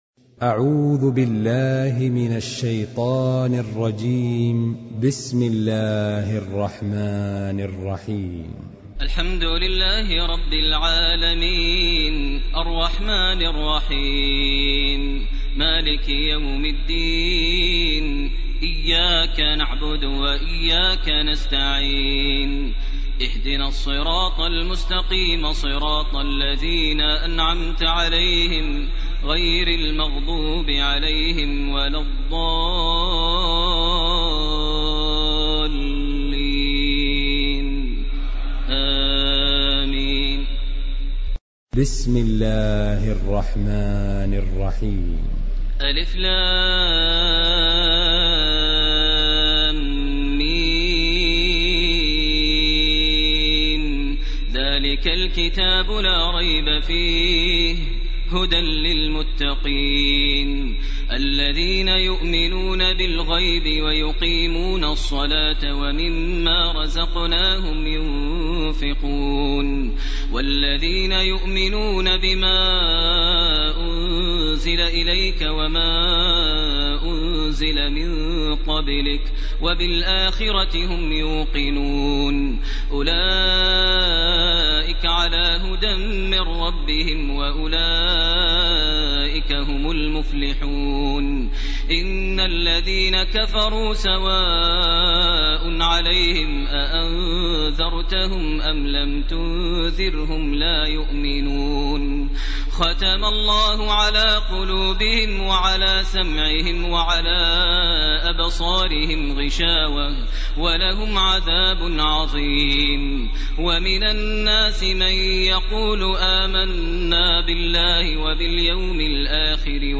سورة الفاتحة وسورة البقرة 1- 74 > تراويح ١٤٢٩ > التراويح - تلاوات ماهر المعيقلي